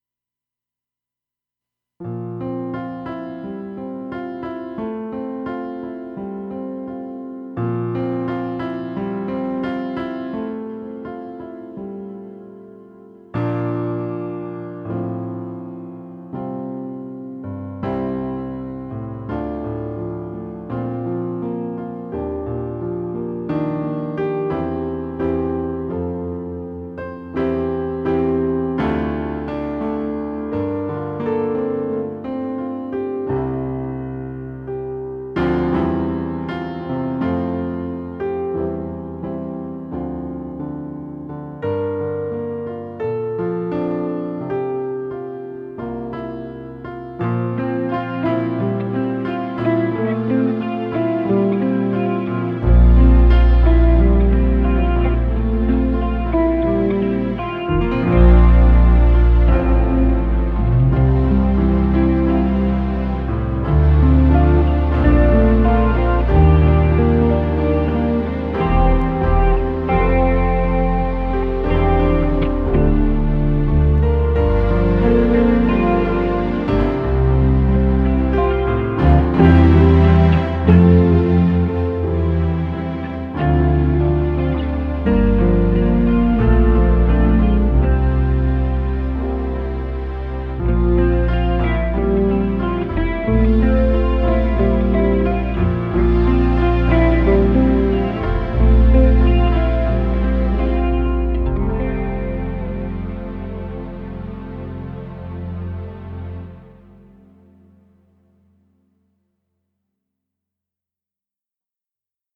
2025 version - no vocals